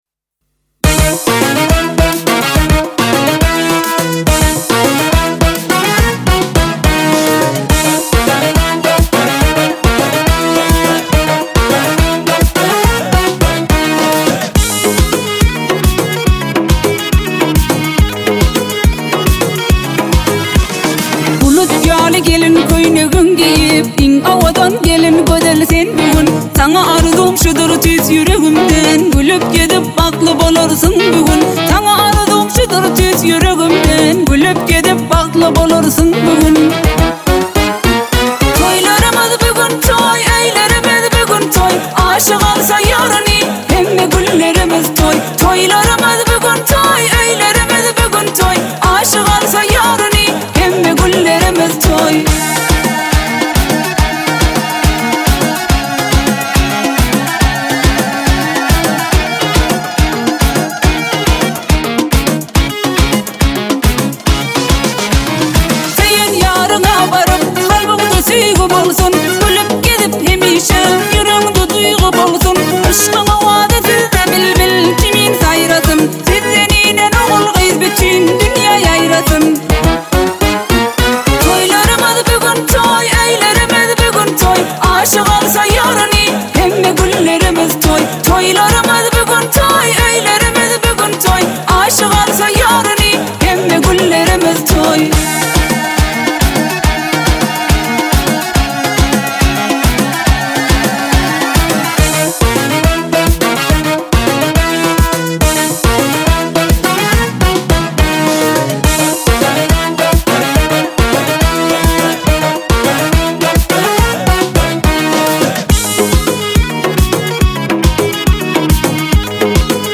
آهنگ ترکمنی